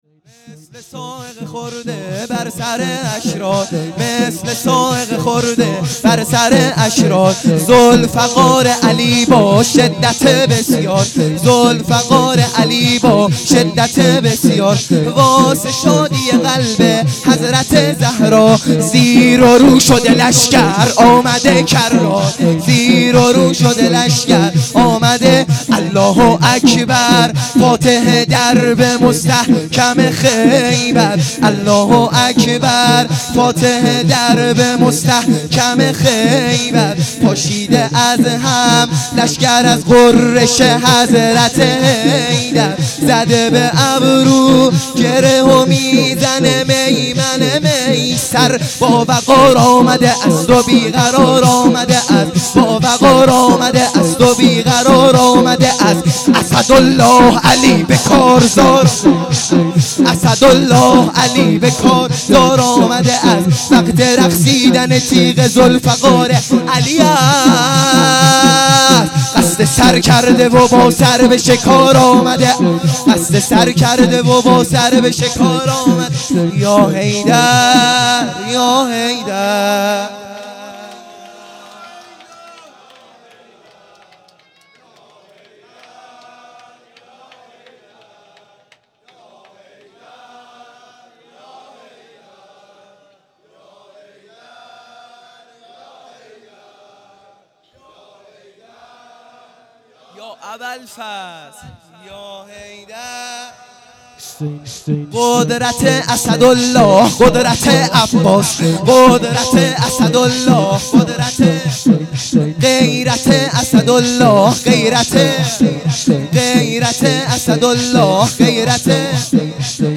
شور | مثل صاعقه خورده